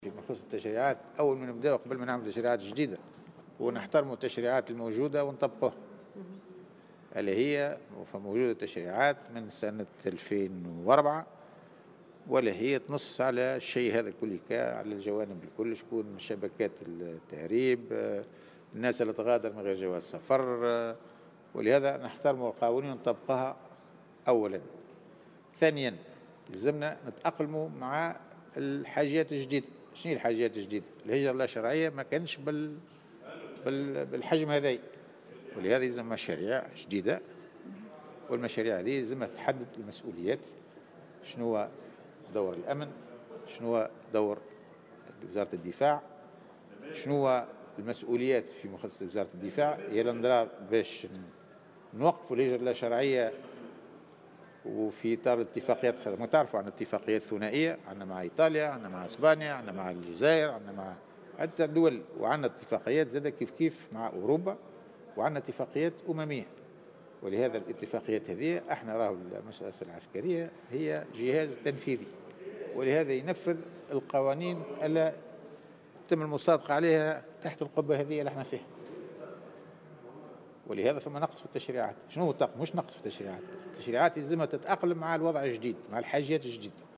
وأضاف الزبيدي خلال جلسة استماع له اليوم الخميس بمجلس نواب الشعب أنه يجب مراجعة التشريعات المتعلقة بمكافحة الهجرة غير الشرعية حتى تتلائم مع المتغيرات.